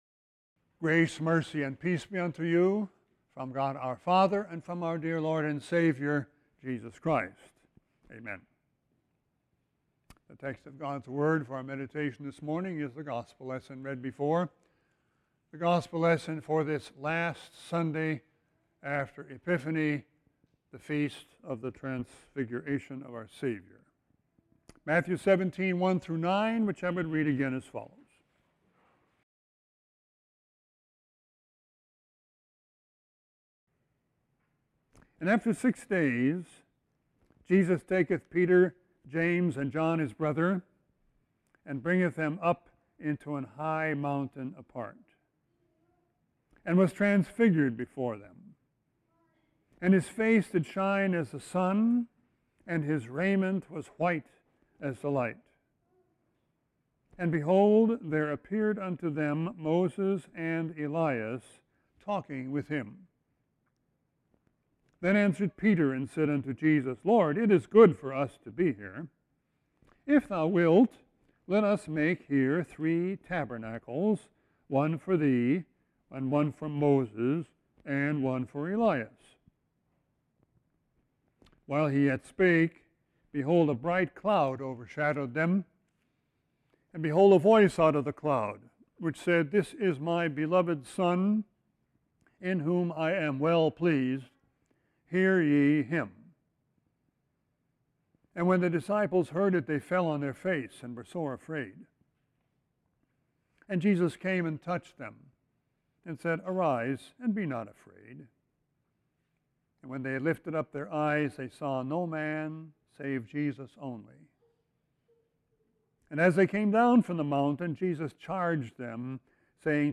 Sermon 2-10-19.mp3